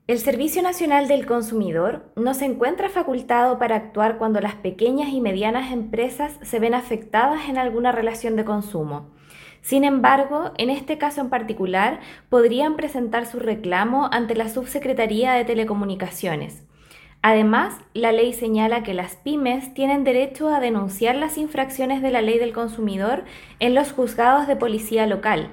Algo que aclaró la directora del Sernac en La Araucanía, Marissa Brieba, indicando que no tienen competencias en la materia y que los afectados pueden realizar las denuncias ante el Juzgado de Policía Local.